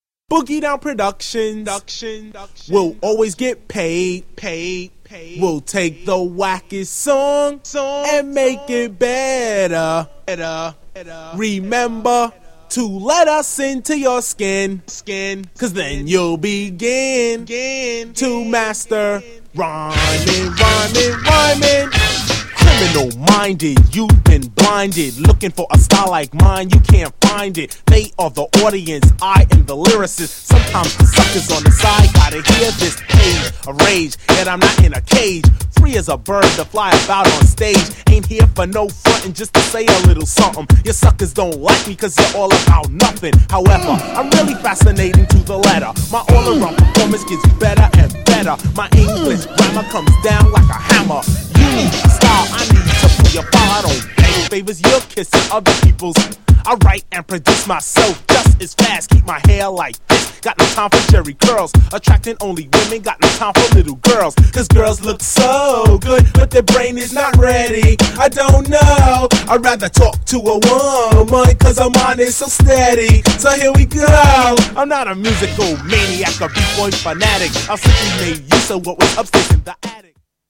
GENRE Hip Hop
BPM 91〜95BPM